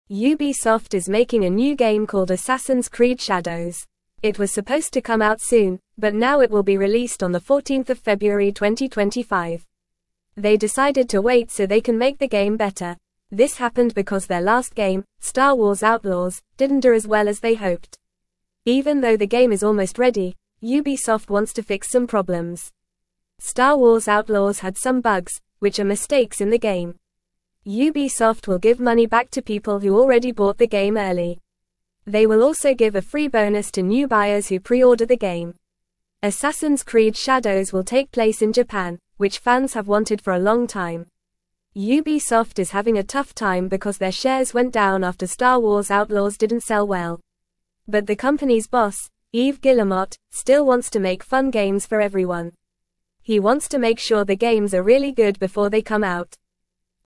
Fast
English-Newsroom-Lower-Intermediate-FAST-Reading-Ubisoft-delays-new-game-to-make-it-better.mp3